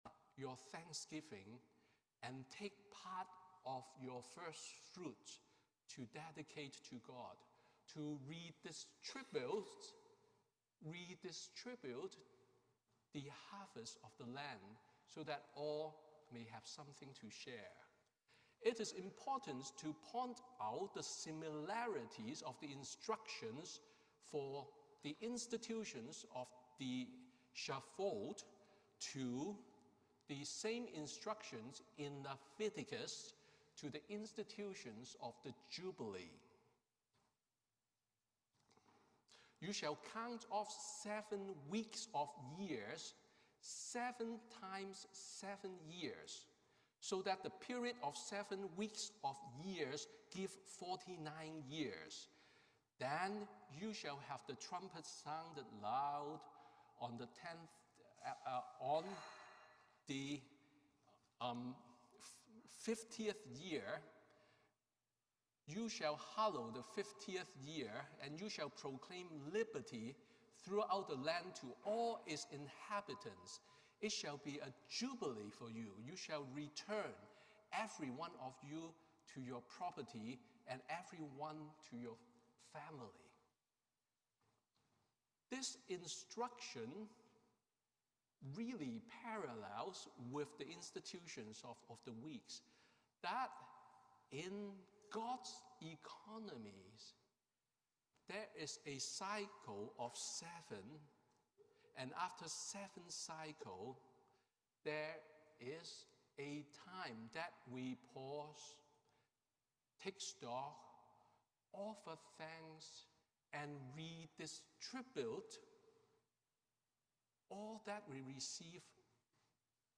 Sermon on the Day of Pentecost